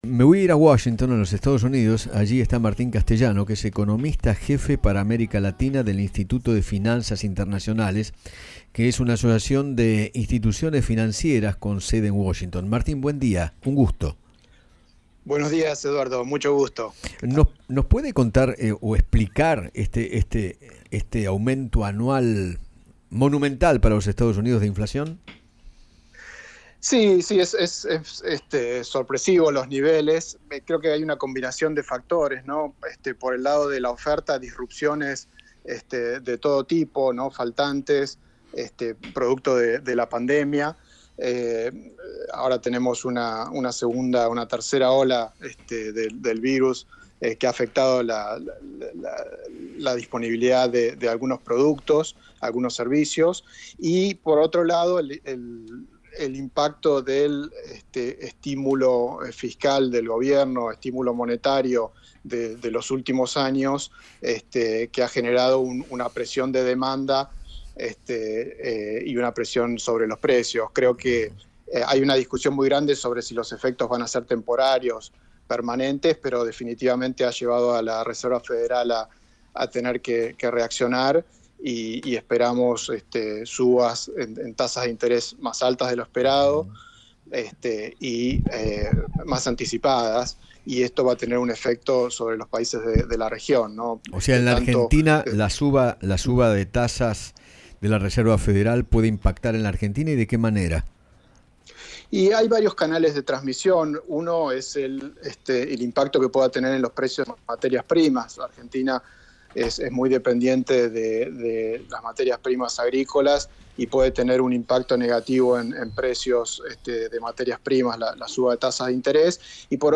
conversó con Eduardo Feinmann sobre el presente económico de la Argentina. Además, habló de las altas cifras de inflación que registró Estados Unidos.